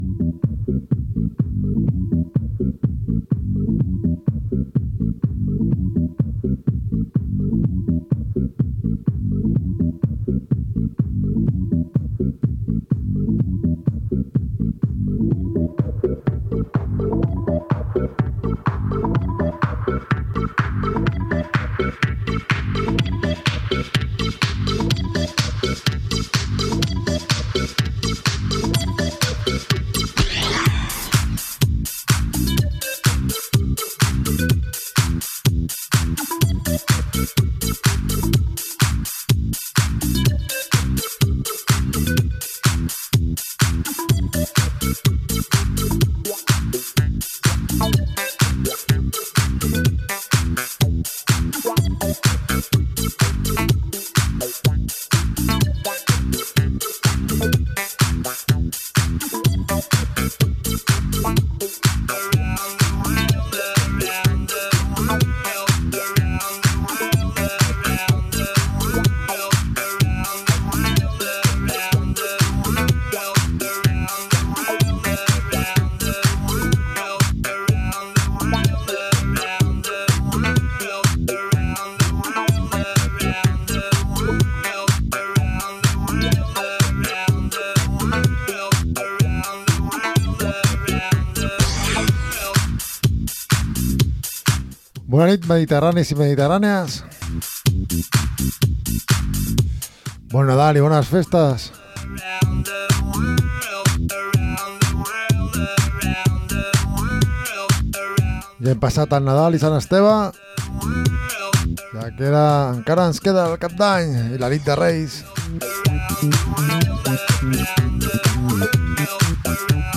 Escolta el millor House dels 80,90,00 a la Mediterranean House Clàssics!!